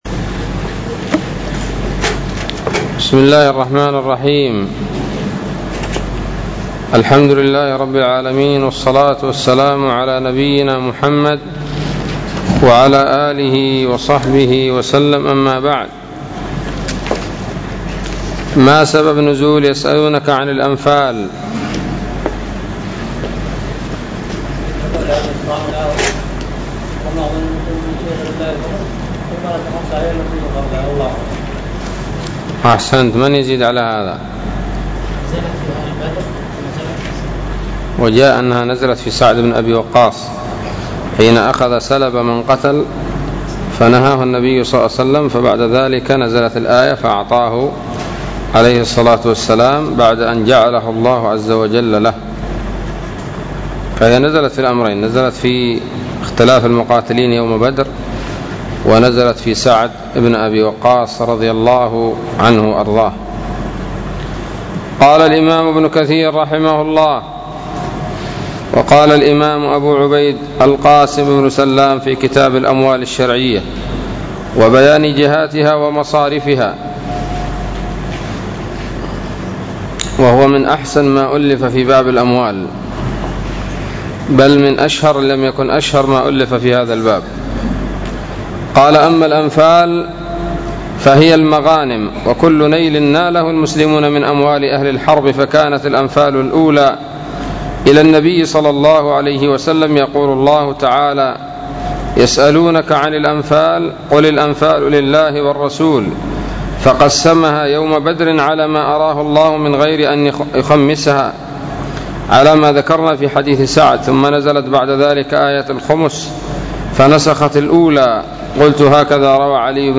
الدرس الثالث من سورة الأنفال من تفسير ابن كثير رحمه الله تعالى